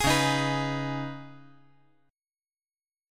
Listen to Db7sus2 strummed